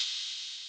TM88 - OPEN HAT (3).wav